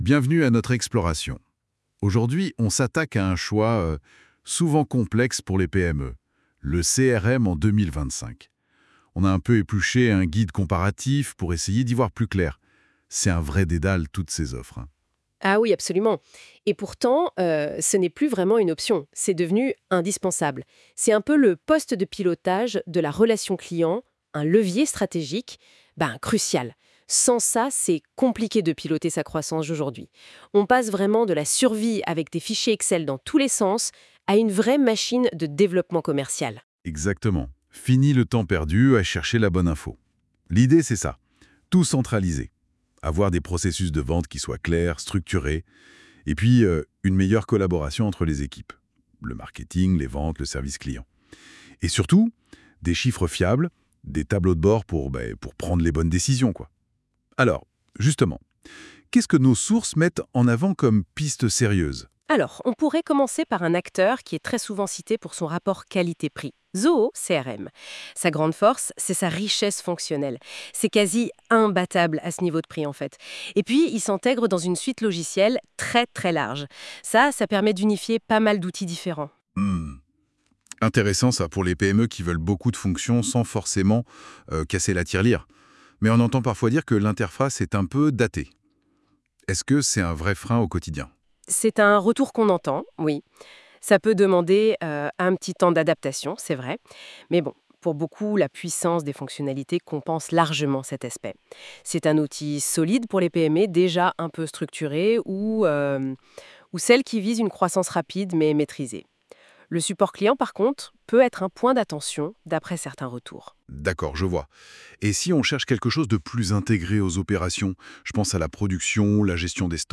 Nous avons intégré un podcast généré automatiquement grâce à NotebookLM, un outil d’intelligence artificielle.